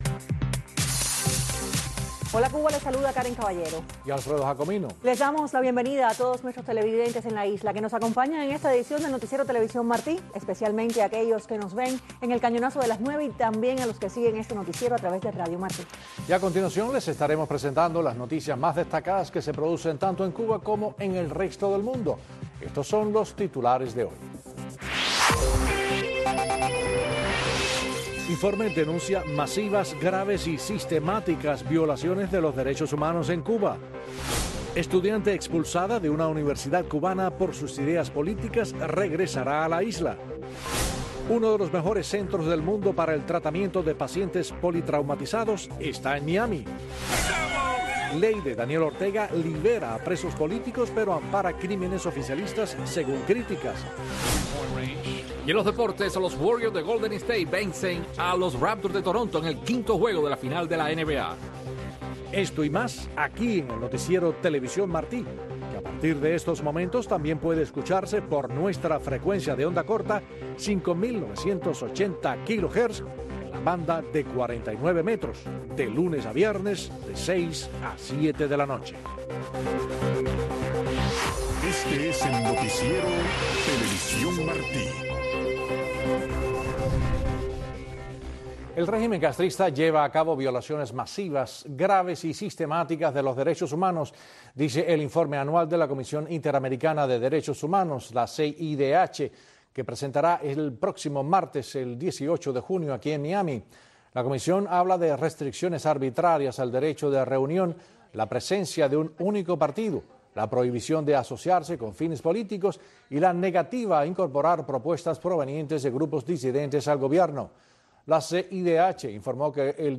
Noticiero de TV Martí